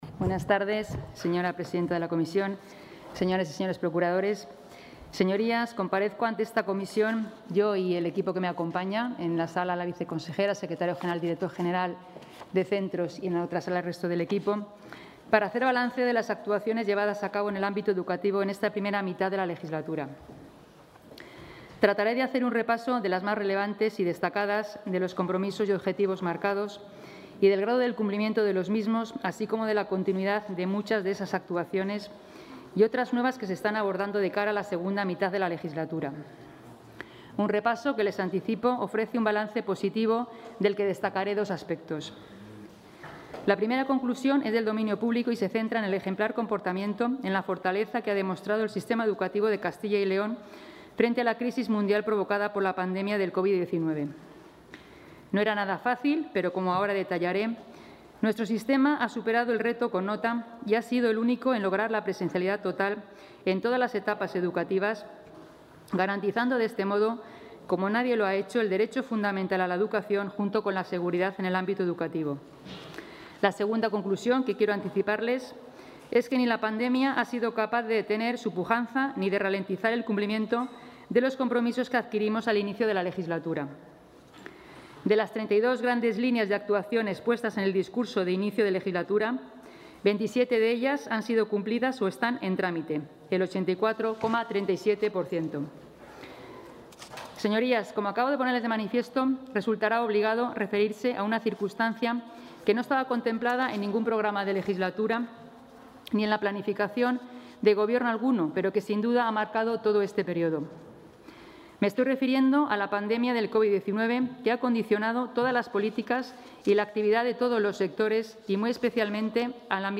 La consejera de Educación, Rocío Lucas, ha comparecido esta tarde en las Cortes de Castilla y León para hacer balance de los dos...
Comparecencia de la consejera de Educación.